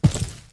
FootstepHeavy_Concrete 05.wav